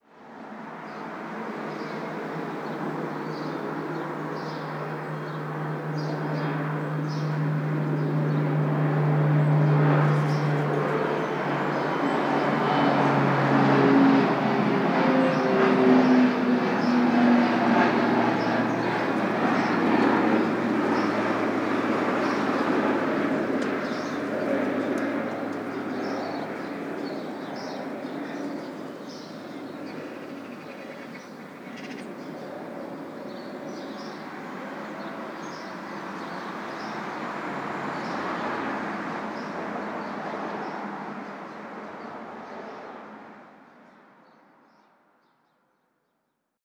mixkit-urban-ambience-with-traffic-2929.wav